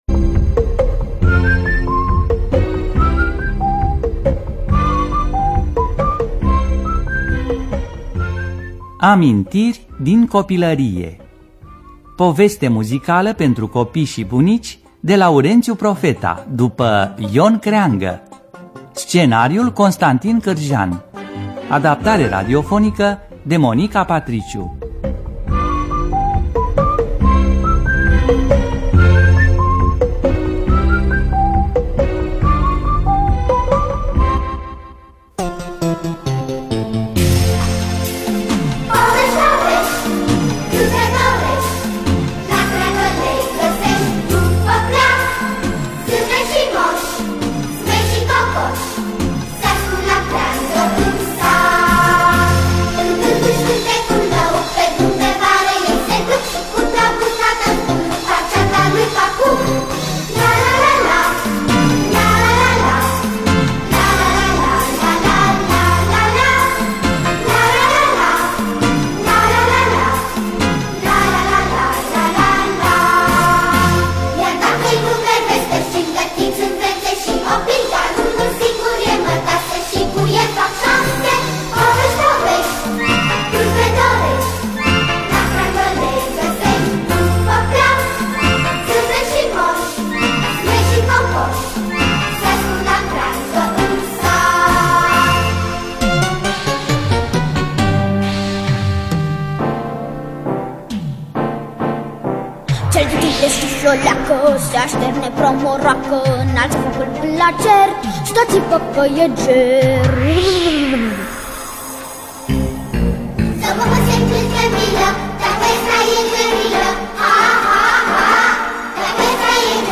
Poveste muzicală de Laurențiu Profeta.